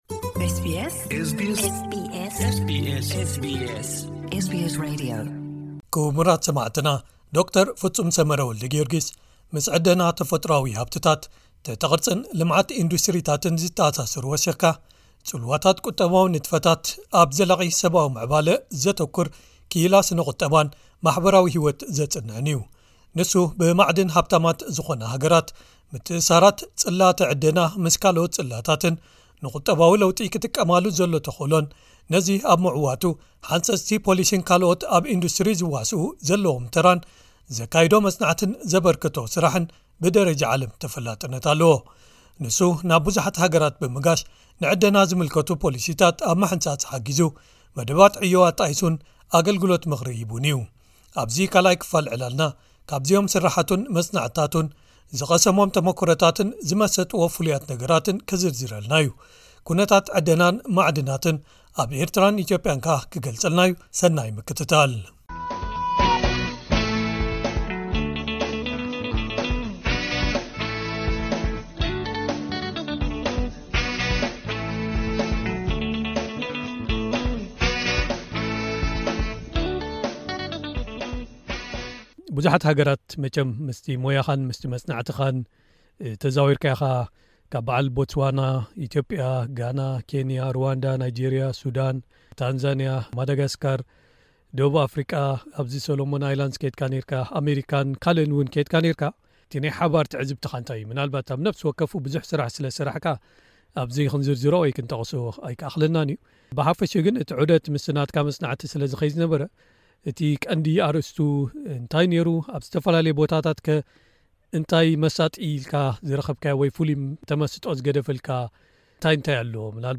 ኣብ’ዚ 2ይ ክፋል ዕላልና ካብ’ዚኦም ስራሓቱን መጽናዕትታቱን፣ ዝቐሰሞም ተሞክሮታትን ዝመሰጥዎ ፍሉያት ነገራትን ክዝርዝረልና’ዩ። ኩነታት ዕደናን ማዕድናትን ኣብ ኤርትራን ኢትዮጵያን ከኣ ብሓፈሽኡ ክገልጸልና’ዩ።